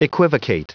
Prononciation du mot equivocate en anglais (fichier audio)
Prononciation du mot : equivocate